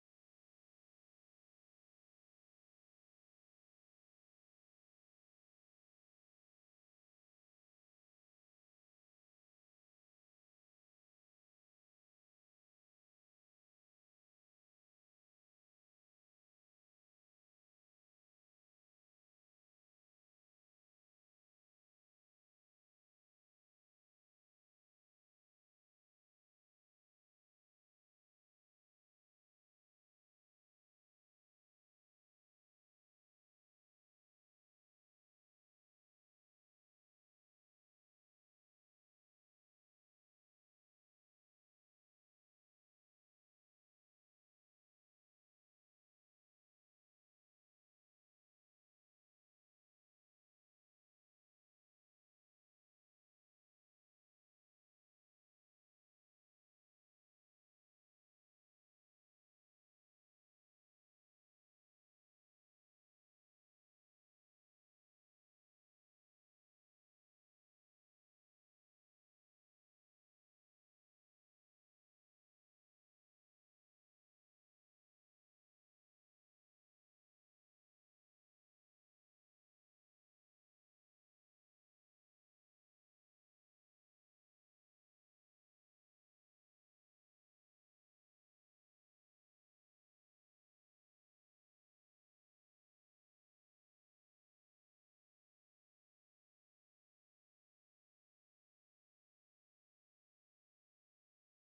ライブ・アット・アンティーブ・ジャズ、アンティーブ、フランス 07/20/1996
※試聴用に実際より音質を落としています。